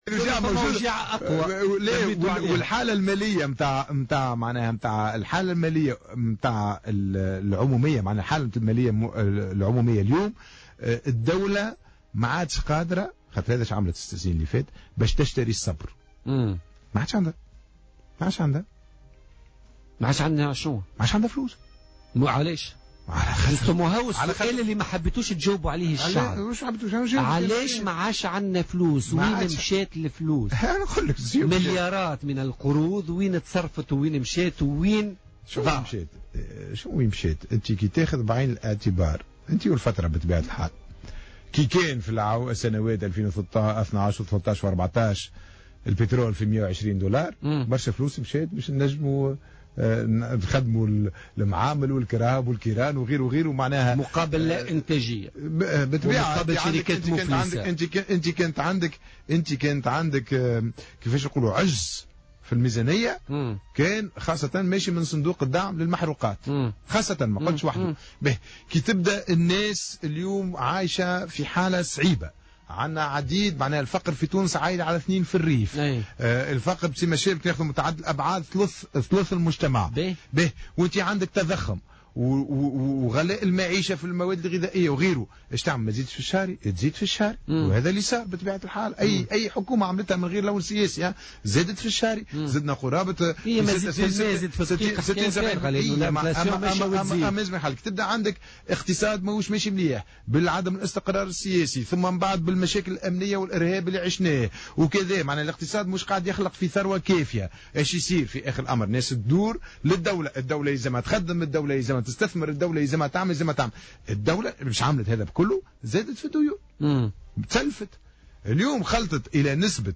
وأوضح في مداخلة له اليوم في برنامج "بوليتيكا" أن المالية العمومية أصبحت في وضعية صعبة بسبب تضاعف المديونية وتضخم الأسعار وانتشار الفقر وغلاء المعيشة.